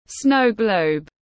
Quả cầu tuyết tiếng anh gọi là snow globe, phiên âm tiếng anh đọc là /ˈsnəʊ ˌɡləʊb/
Snow globe /ˈsnəʊ ˌɡləʊb/